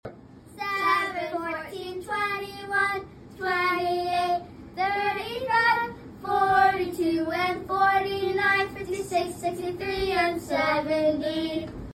Count by 7’s to the tune of Mary Had a Little Lamb! This fun song helps students remember one of the hardest multiples: 7!